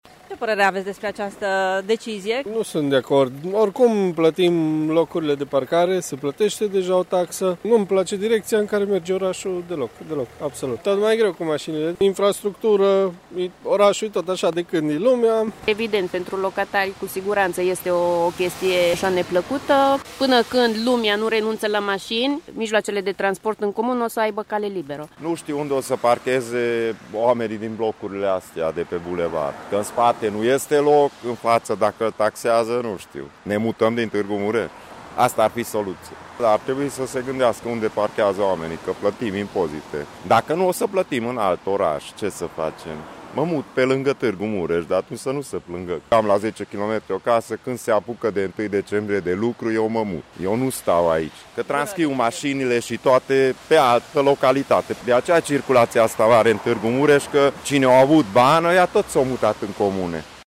Târgumureșenii sunt nemulțumiți de noile taxe de parcare ce vor fi impuse iar unii se gândesc să se mute din oraș: